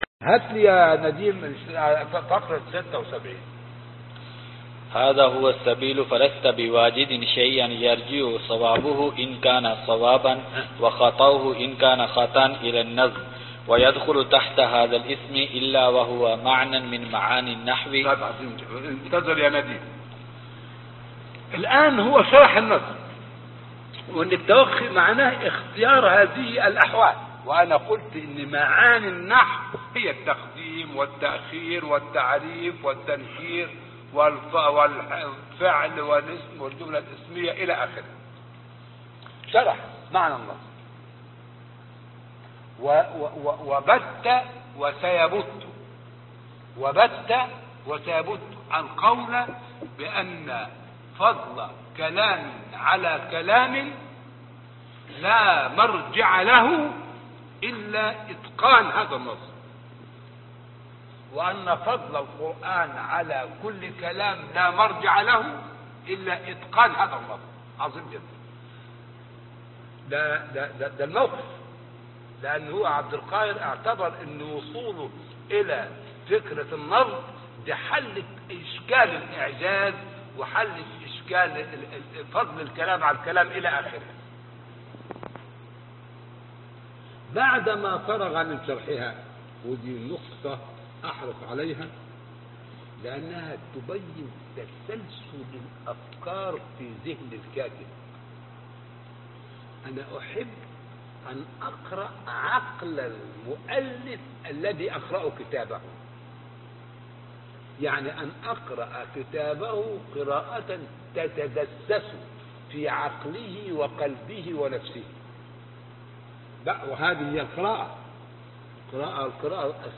الدرس الثامن: شرح كتاب دلائل الإعجاز